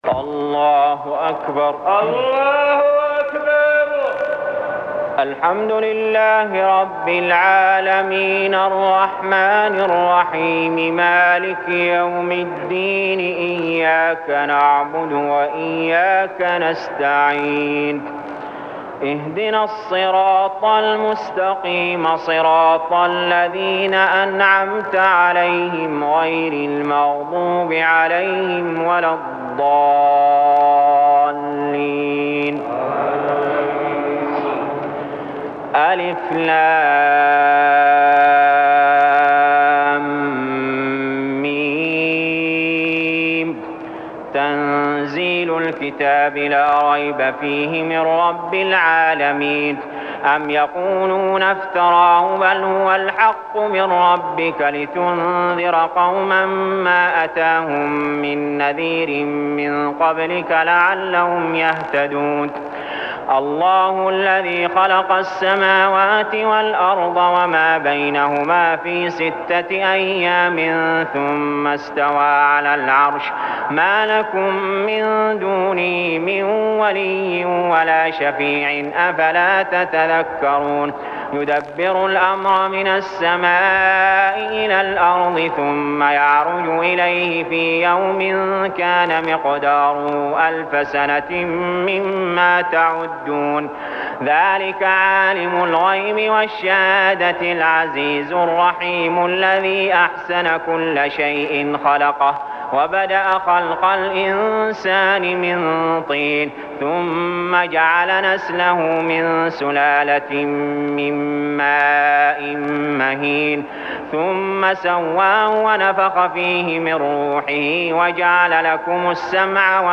المكان: المسجد الحرام الشيخ: علي جابر رحمه الله علي جابر رحمه الله السجدة